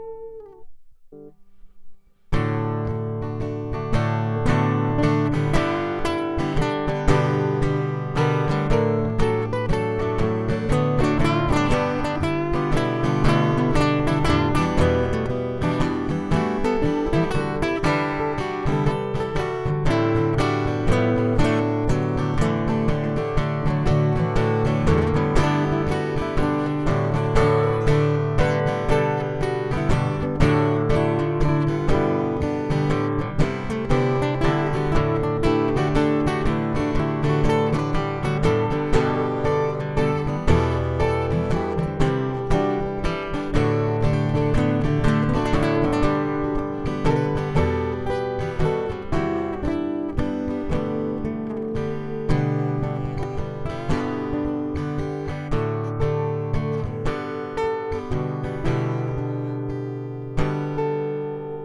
И поскольку нет никаких задержек, прокладка нескольких линий отведений - это очень просто, как показано в моем первом цикле:
Дополнительная информация: Построен как танк (полностью металлический корпус), ключевые инструкции напечатаны на обратной стороне педаль, вход MIC (без фантомного питания), инструментальные и вспомогательные входы, возможность удаленного ножного переключателя, USB-соединение (позволяет PC / MAC просматривать JamMan как устройство чтения Compact Flash), включает 128 МБ CF, поддерживает карты 2 ГБ для часов циклов, хранит 99 петли, включает в себя источник питания, включает в себя метроном с различными звуками и переменными временными сигнатурами и т. д. Это монофоническое устройство.